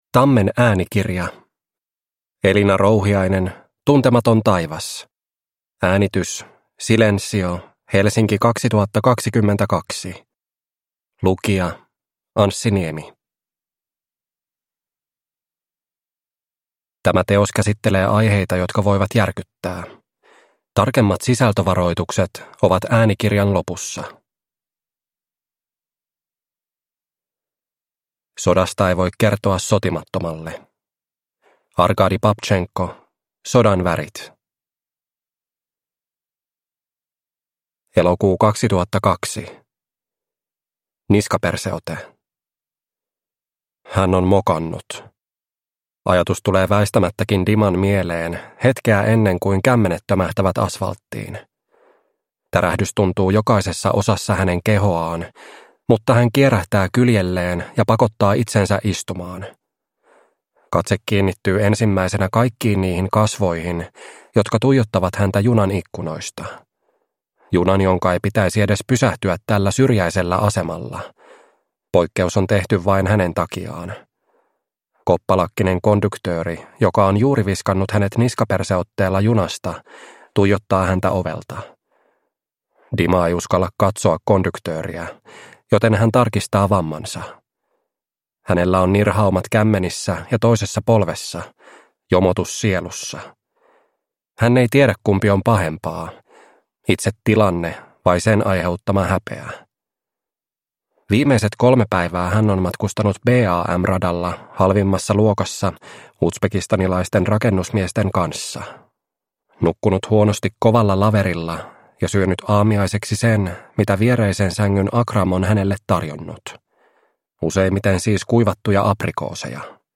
Tuntematon taivas – Ljudbok – Laddas ner